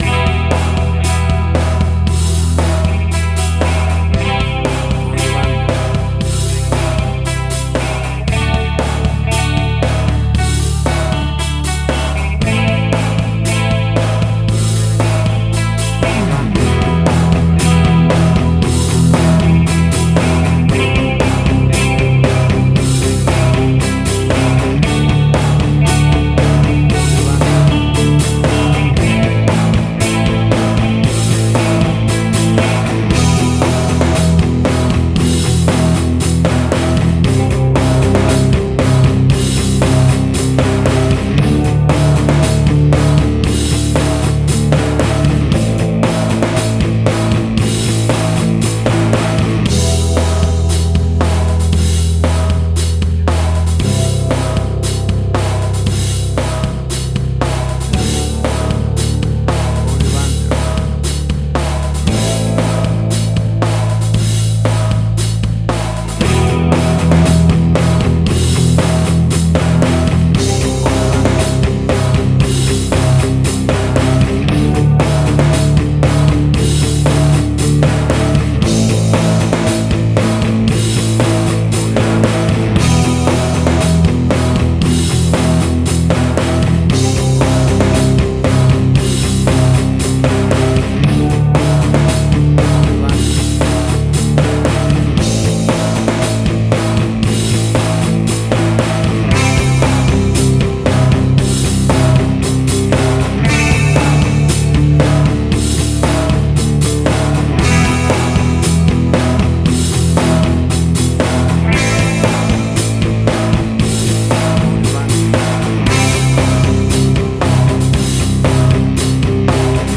Hard Rock
Heavy Metal.
Tempo (BPM): 115